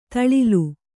♪ taḷilu